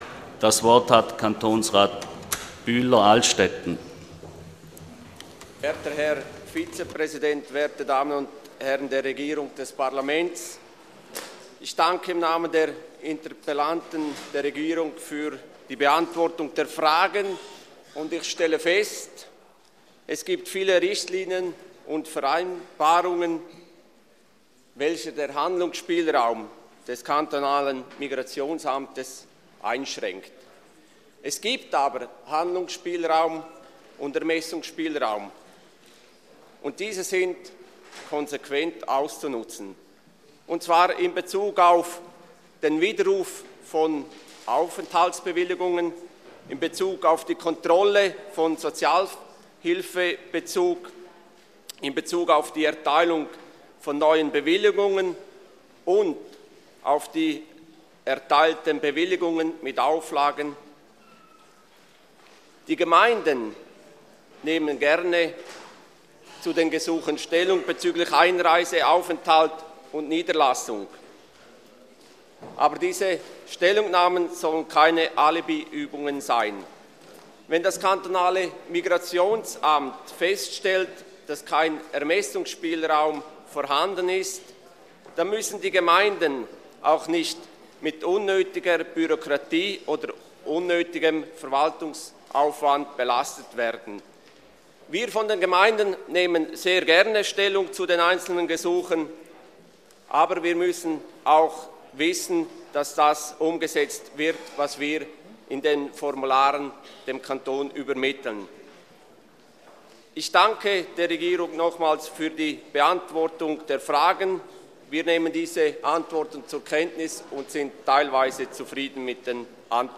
Session des Kantonsrates vom 25. bis 27. Februar 2013